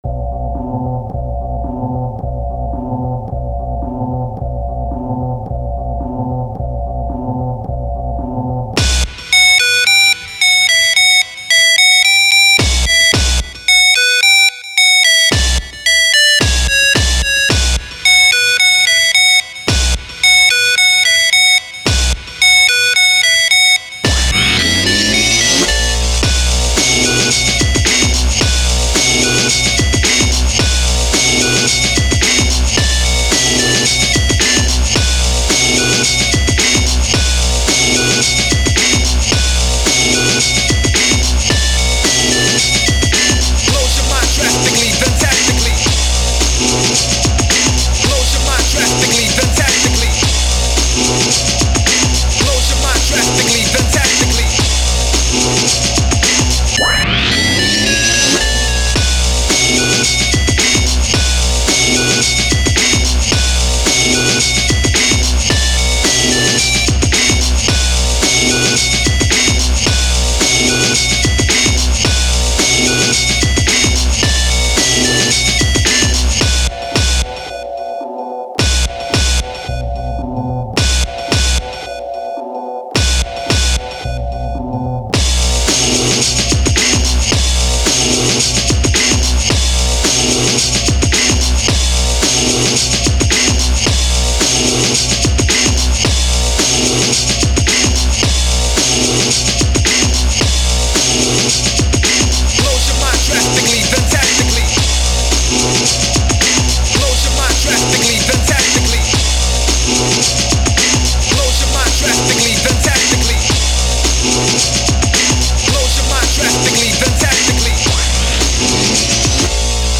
когда запиликало, чуть кровь с ушей не хлынула